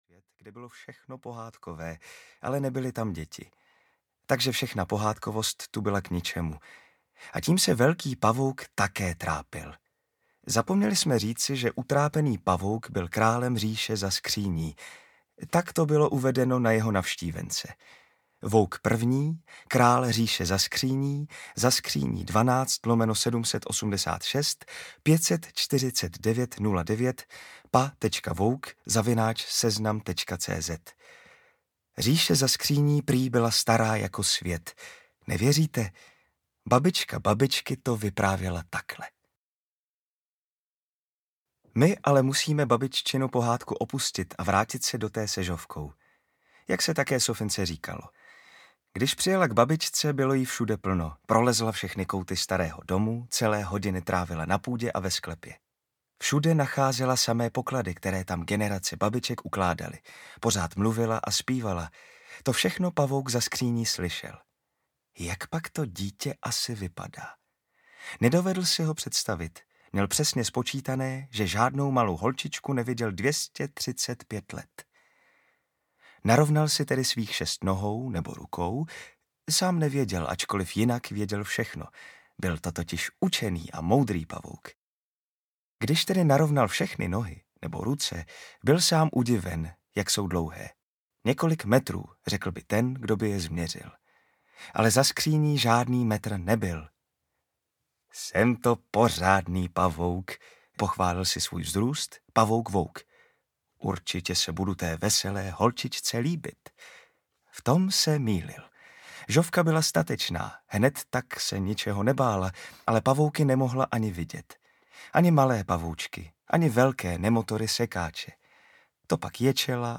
Aby se holky nebály audiokniha
Ukázka z knihy